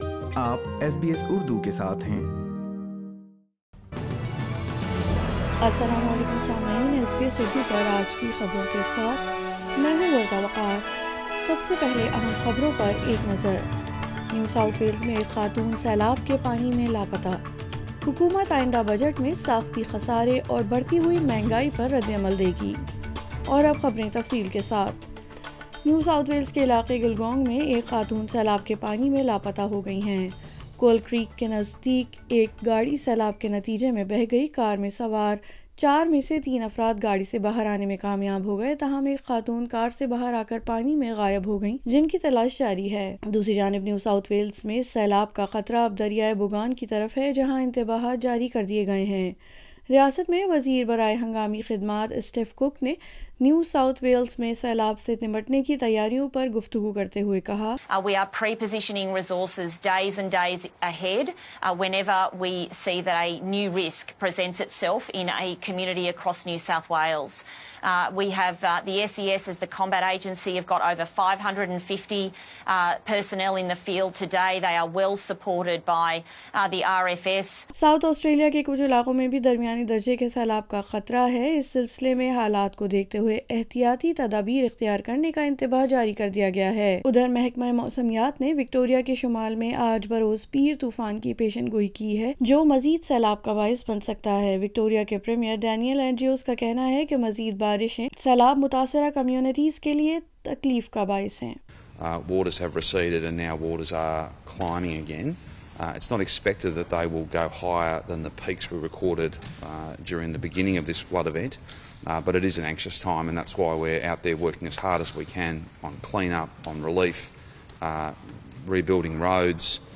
Urdu News 24 October 2022